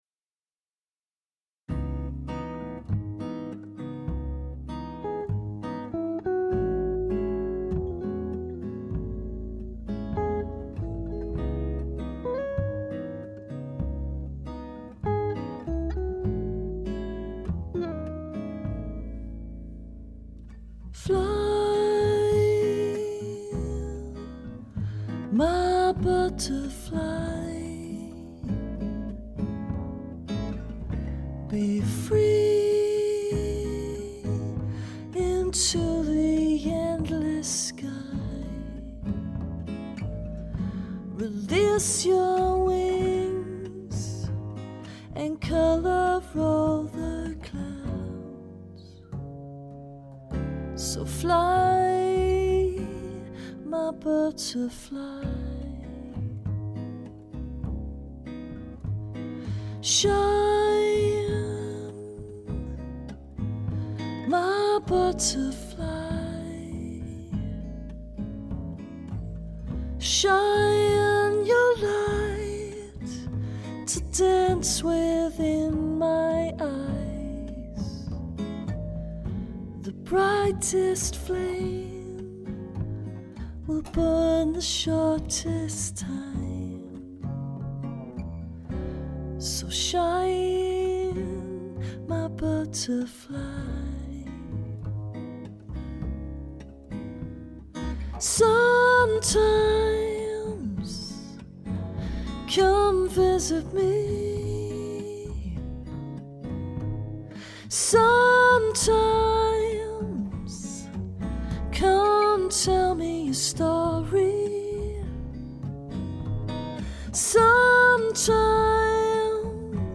ballad Fm vx, piano Freedom in love..